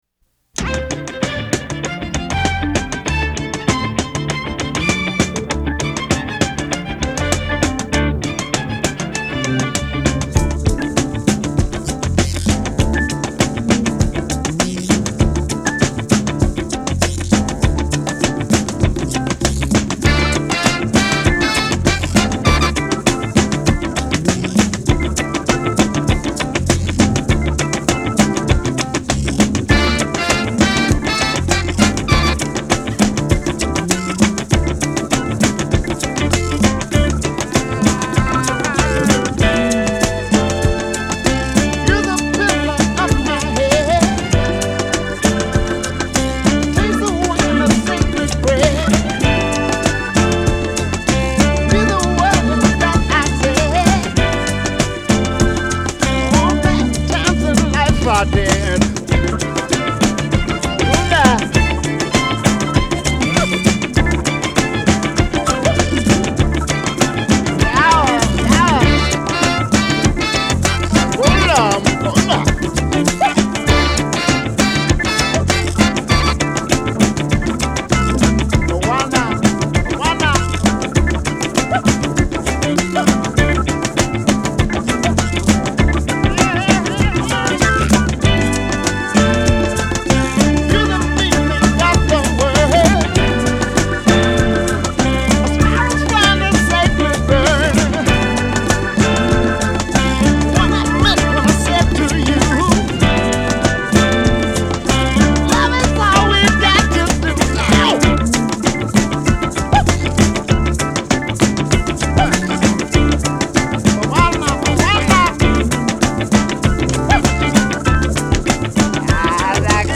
manically funky
Category: Song of the Day, Soul